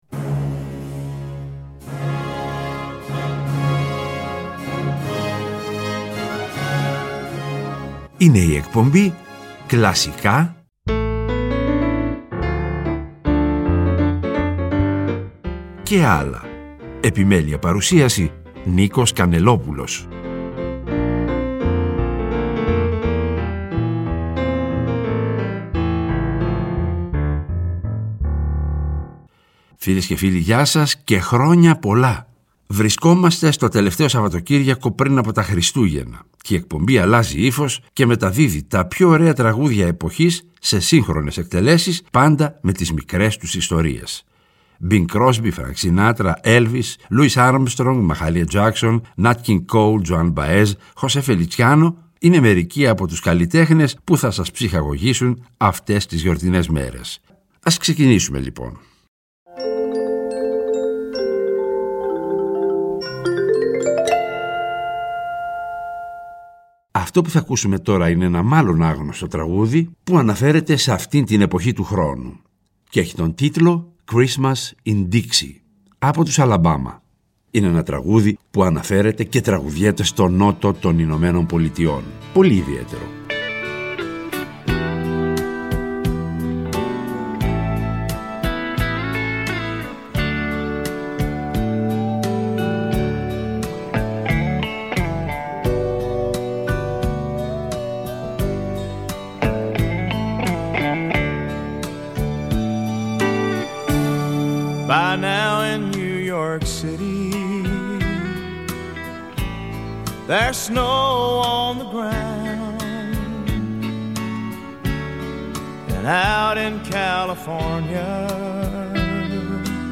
Τραγούδια Εποχής σε Σύγχρονες Εκτελέσεις
Το τελευταίο Σαββατοκύριακο πριν από τα Χριστούγεννα η εκπομπή αλλάζει ύφος και μεταδίδει τα πιο ωραία τραγούδια εποχής σε σύγχρονες εκτελέσεις, πάντα με τις μικρές τους ιστορίες.